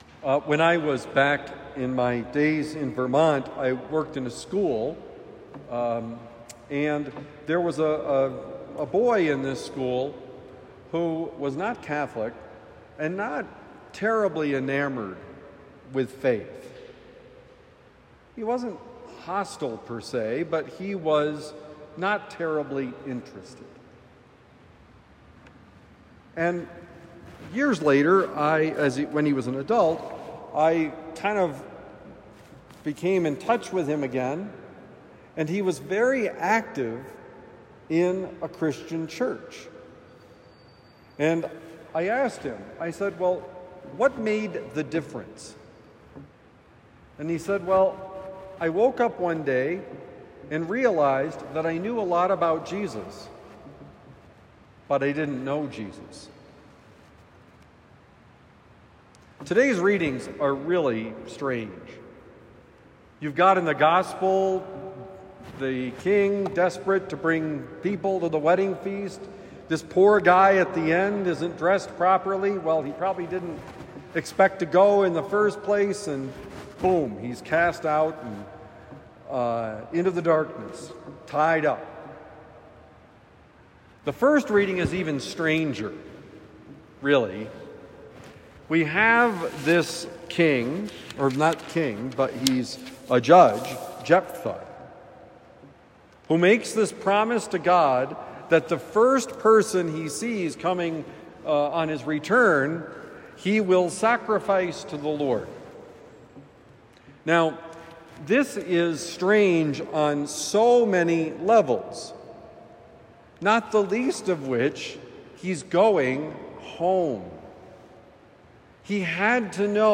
Do you know God?: Homily for Thursday, August 21, 2025